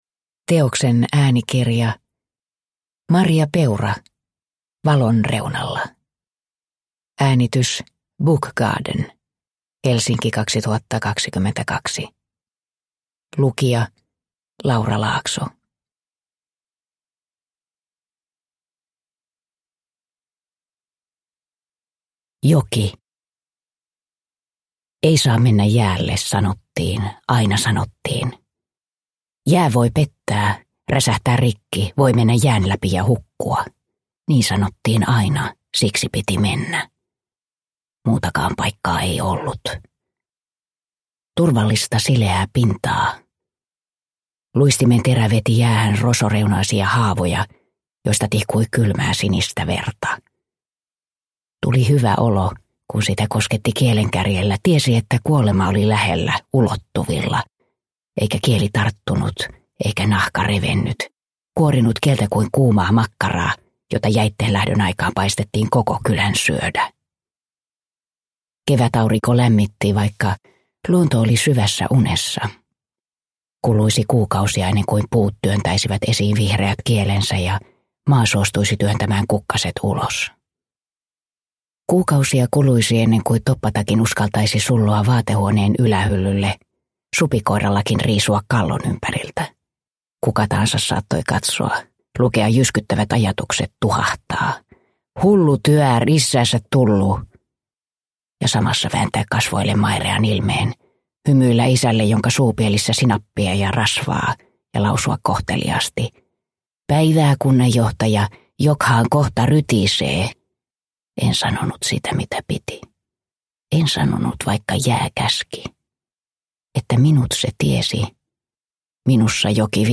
Valon reunalla (ljudbok) av Maria Peura